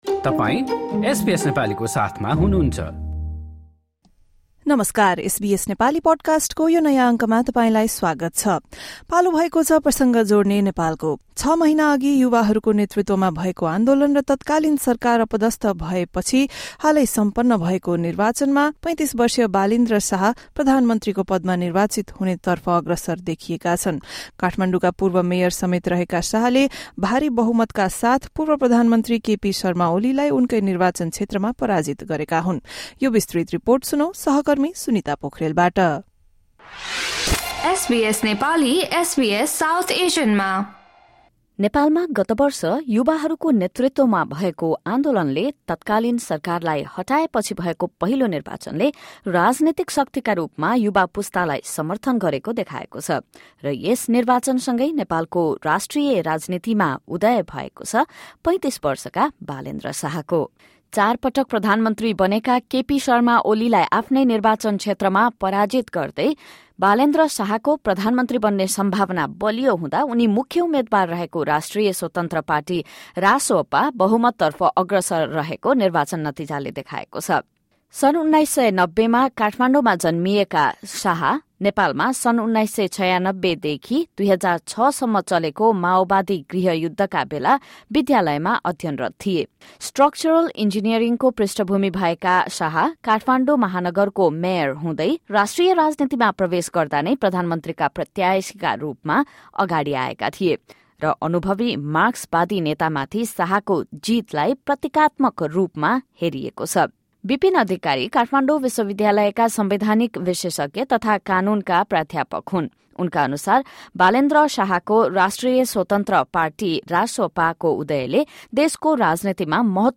Six months after youth-led protests in Nepal ousted the government, voters have elected 35-year-old Balendra Shah. After defeating former prime minister KP Sharma Oli, the former Kathmandu mayor is now poised to lead the country. Listen to a report on how the rapper-turned-politician rose to power.